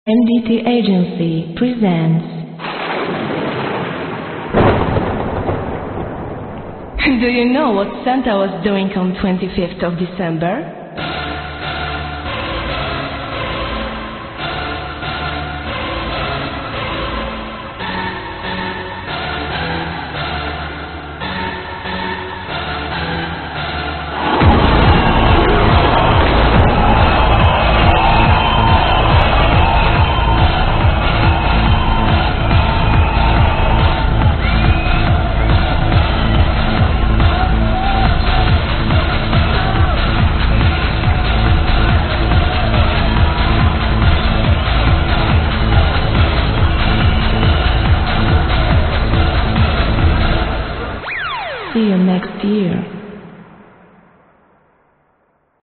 I think the vocals are:
The fact that she doesn't use articles, and her accent makes me think that she's either Russian or Polish.